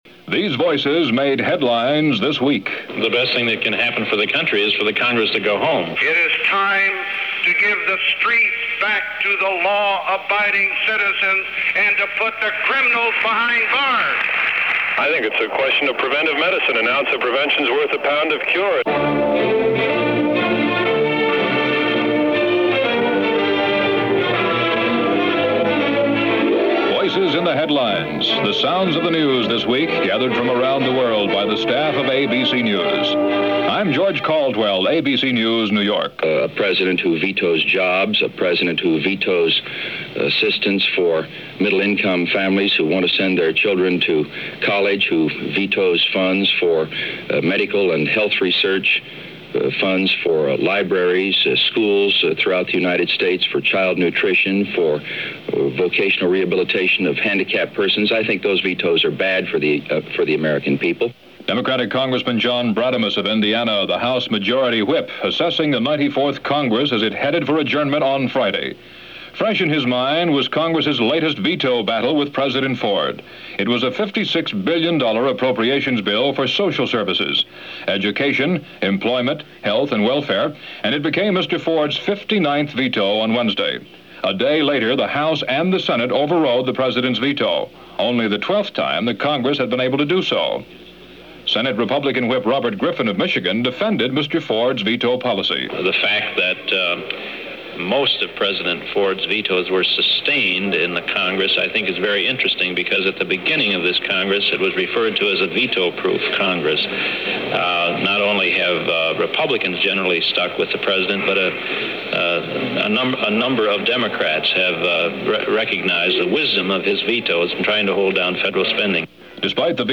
Campaign '76 - Pressing Flesh - Slapping Backs - Kissing Babies - October 3, 1976 - ABC World News This Week.